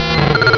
pokeemerald / sound / direct_sound_samples / cries / chinchou.aif